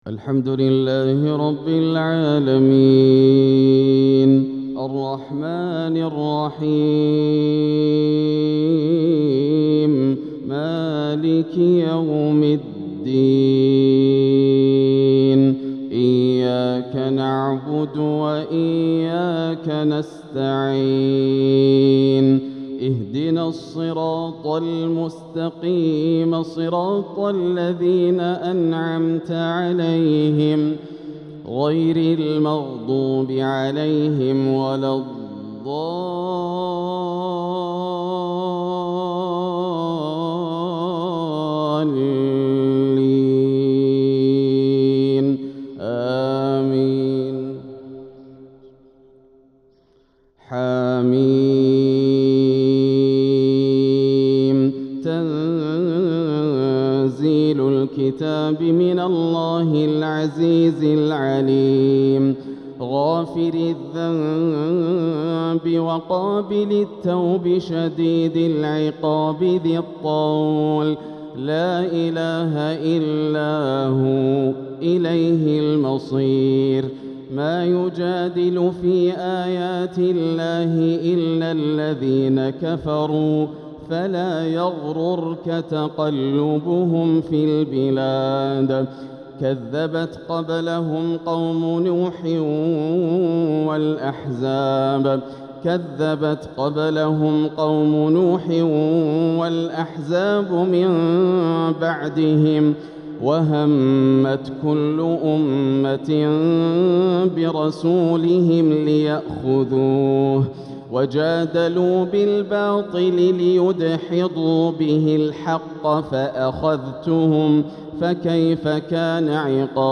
تلاوة مؤثرة لفواتح سورة غافر| 1-20 | فجر الثلاثاء 3-7-1447هـ > عام 1447 > الفروض - تلاوات ياسر الدوسري